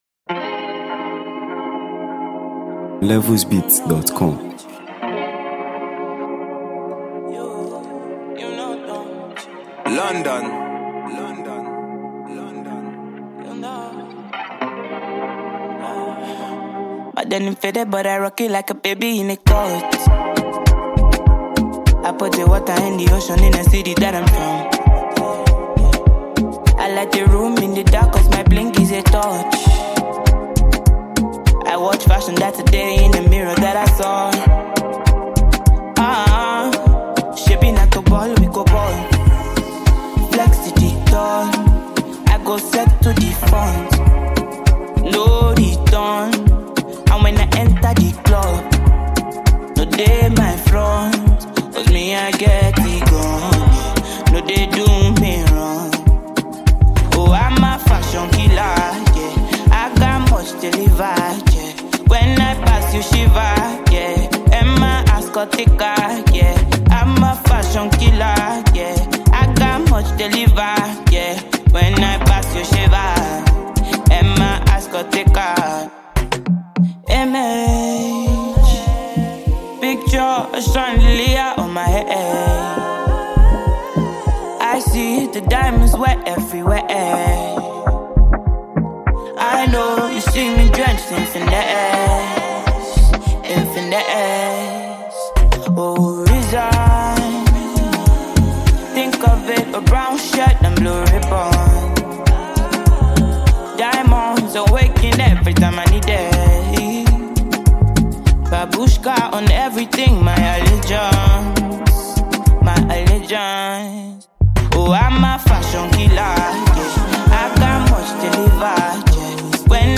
Nigeria Music 2025 2:58
Afropop